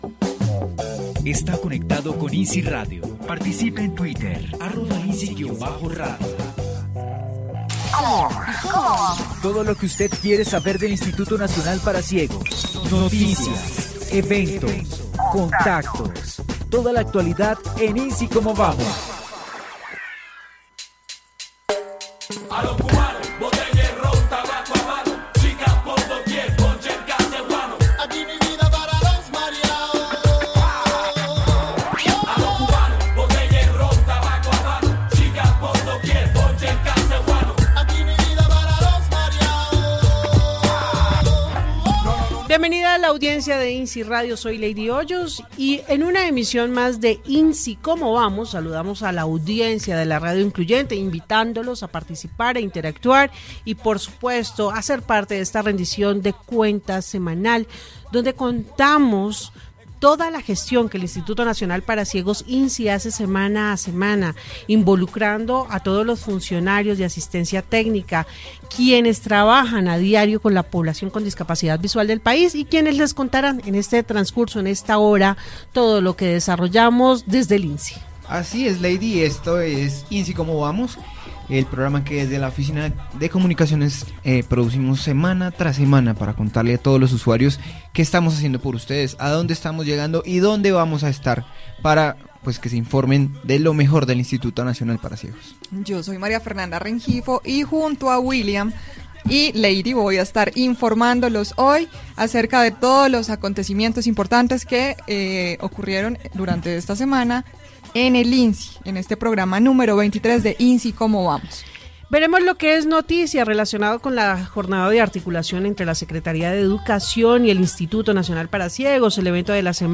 Audio Noticiero informativo institucional del INCI del 09 de agosto de 2019 Numero de serie 3358 Fecha Vie